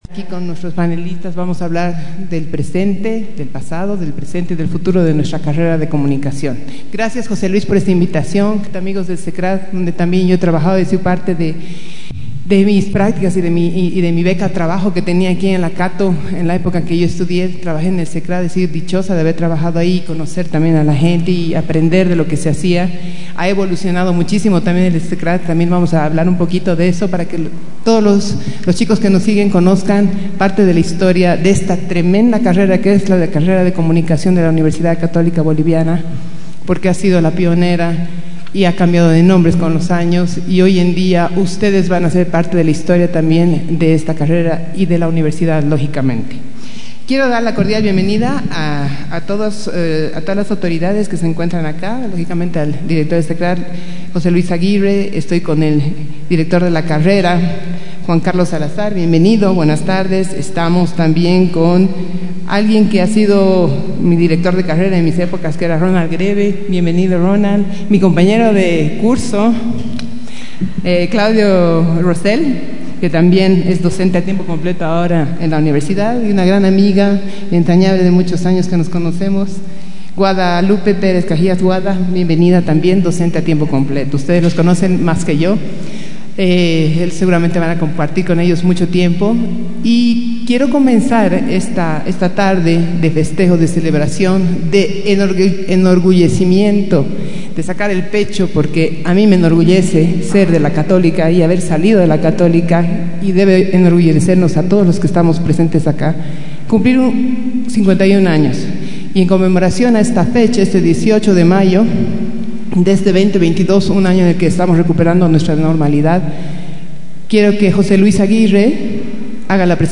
El formato de entrevista adoptado para esta oportunidad permitió compartir ante la audiencia de estudiantes, docentes y seguidores por redes sociales un espacio dinámico, dialógico y altamente cálido recuperando distintos momentos de la Carrera, ratificando desde todo ellos la fortaleza institucional de la U.C.B. en materia de la formación de comunicadores sociales.